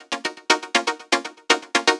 TSNRG2 Lead 018.wav